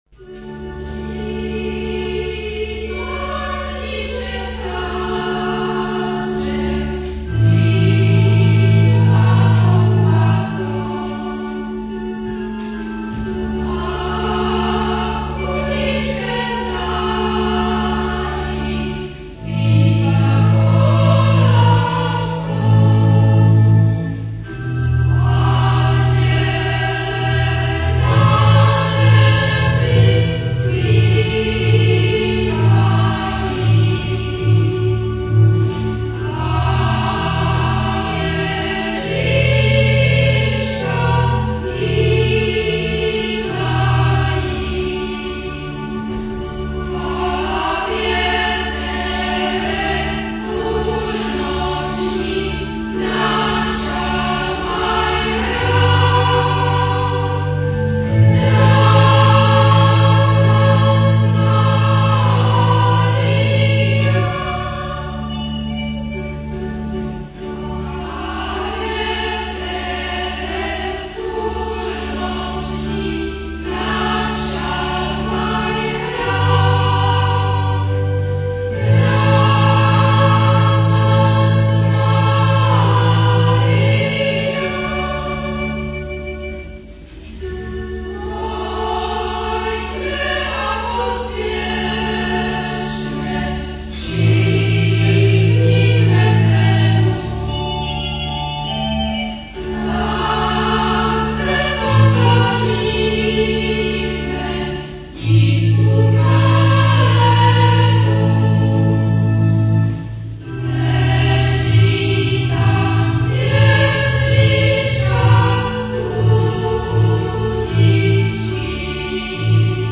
vánoce v kostele Tvarožná 24.12.1983 a 1.1.1984
varhany
Originální nahrávka dochovaná z let 1983/4.
Klíčová slova: Vánoce, liturgická hudba, koledy,